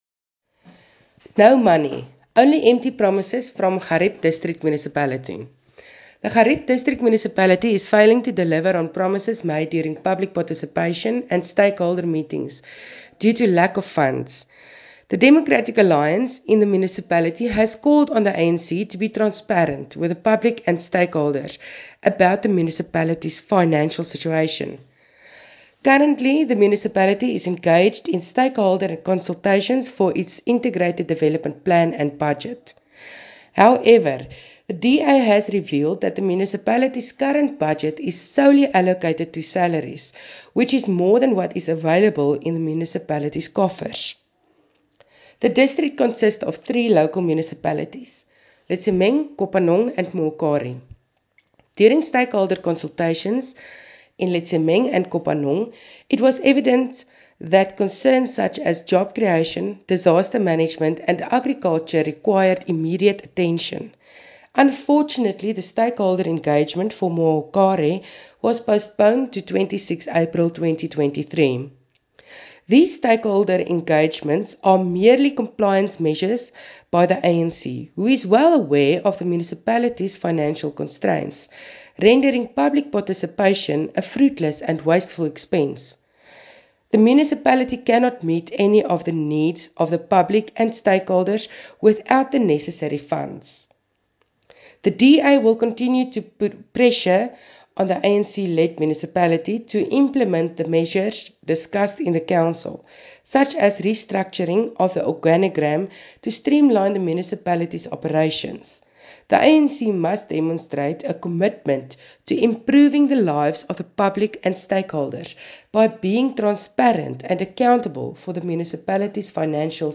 Afrikaans soundbites by Cllr Mariska Potgieter and